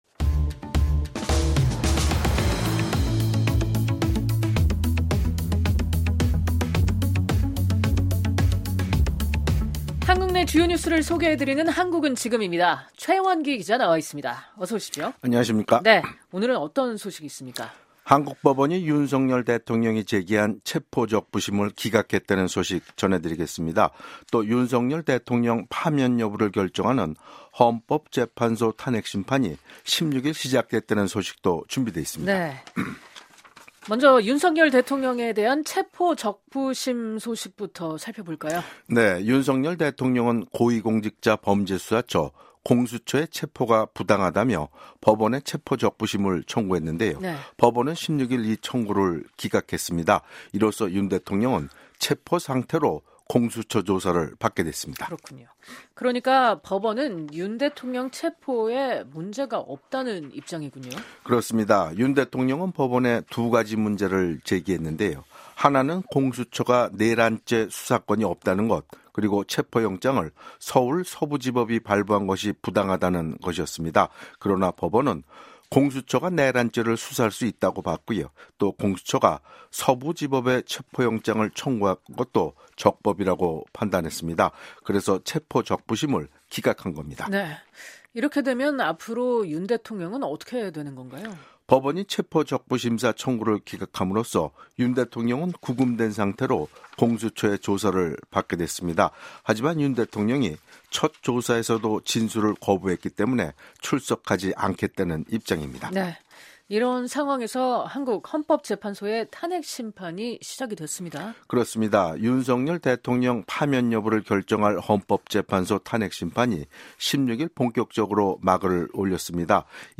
한국 내 주요 뉴스를 소개해 드리는 ‘한국은 지금’입니다.